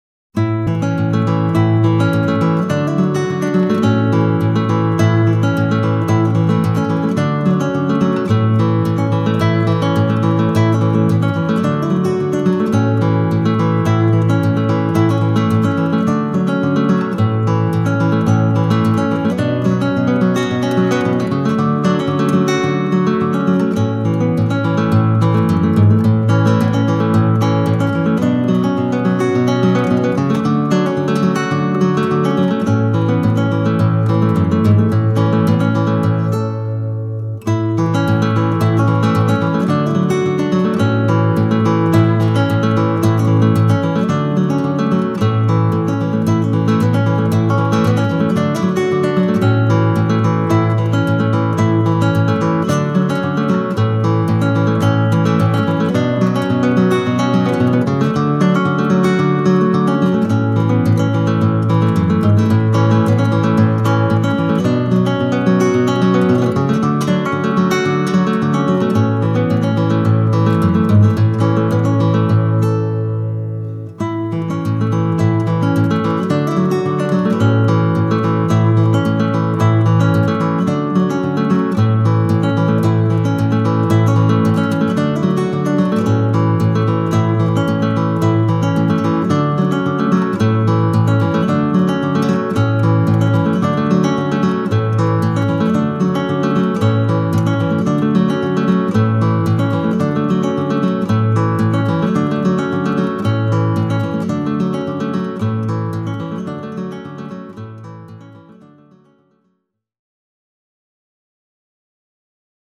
„Der Olivenbaum“ ist eines meiner absoluten Fingerpicking Lieblingsstücke!